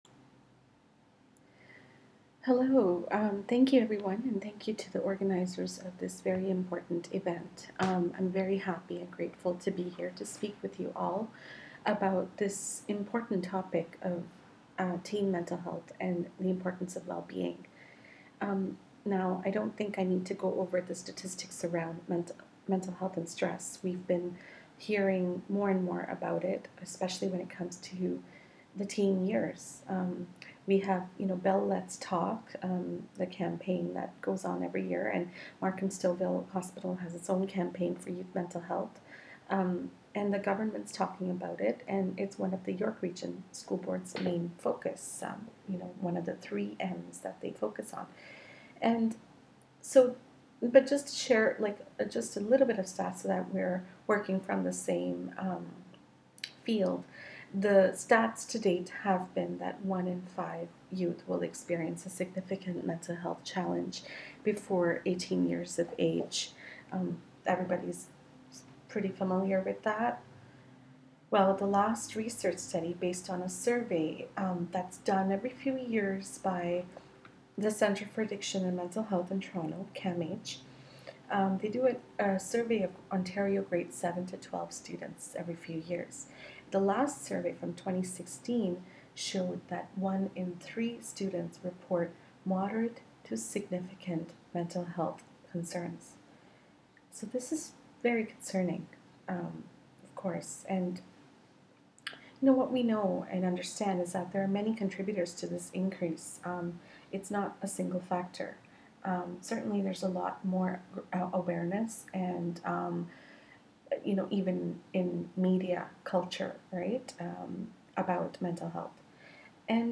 After all, we can enjoy whatever time we have remaining of the lovely long summer days and beautiful sunsets 🙂 So for this post I actually decided to publish a talk I had given back in April at a local high school as part of their inaugural Wellness Event for families. I was invited as a keynote speaker and spoke about the topic of Teen mental health and wellbeing.
(P.S. The audio is kind of faint and so you have to turn up your volume.